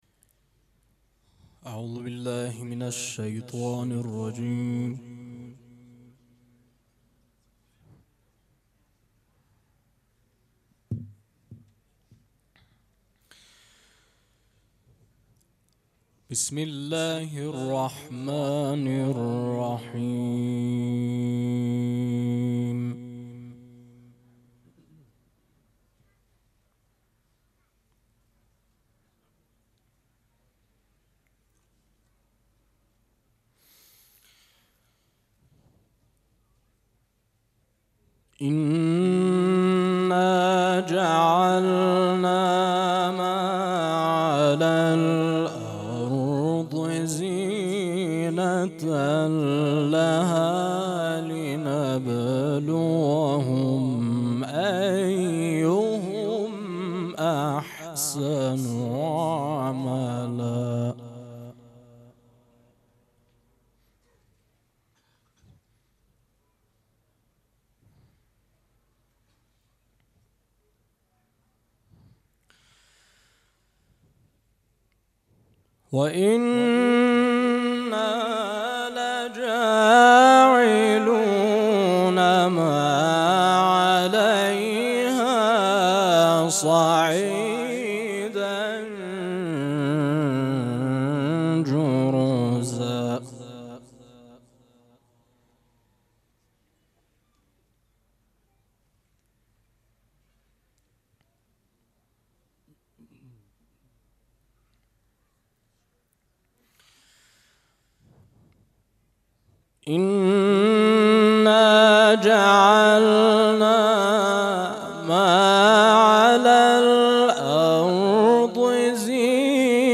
مراسم عزاداری شب پنجم محرم الحرام ۱۴۴۷
قرائت قرآن